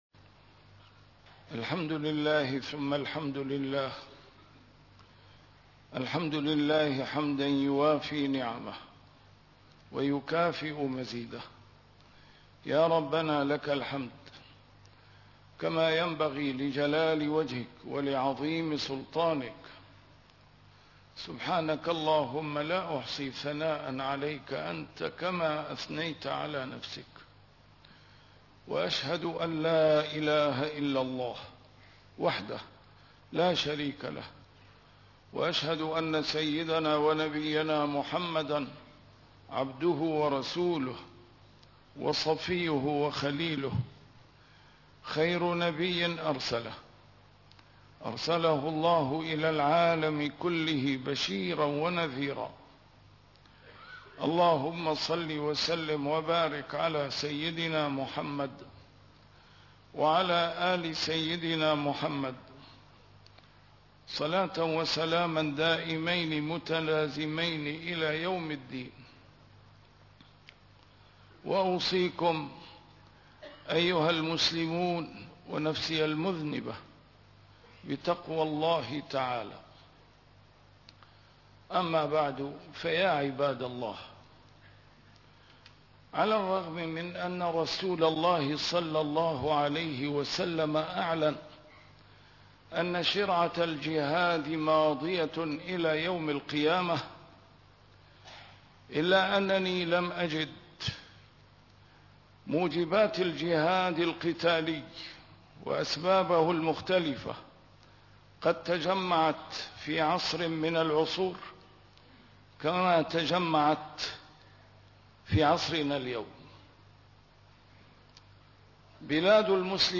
A MARTYR SCHOLAR: IMAM MUHAMMAD SAEED RAMADAN AL-BOUTI - الخطب - الجهاد ماضٍ إلى يوم القيامة